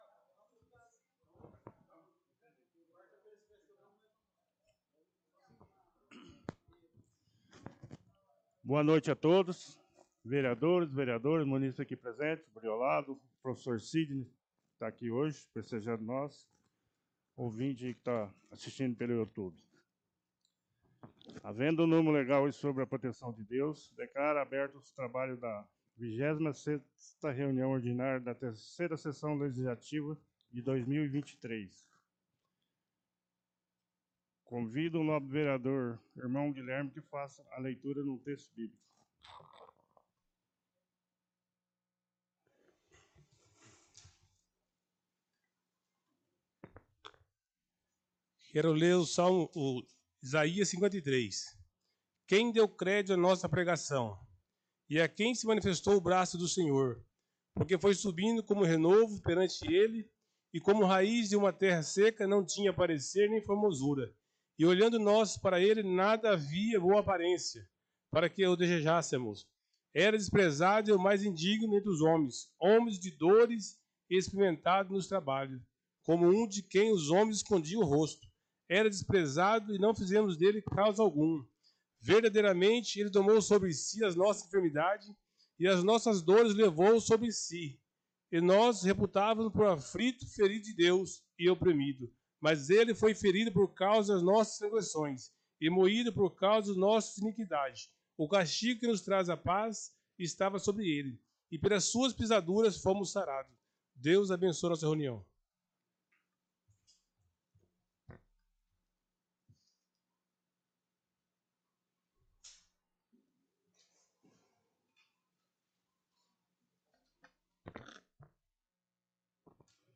26ª Sessão Ordinária - 04-09-23.mp3